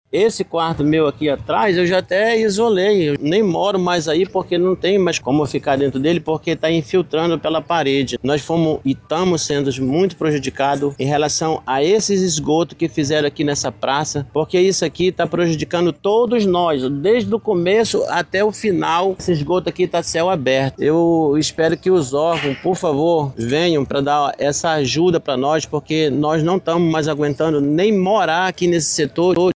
Ainda segundo o morador, parte da sua casa teve que ser interditada por conta das infiltrações causadas pelos vazamentos da tubulação do esgoto.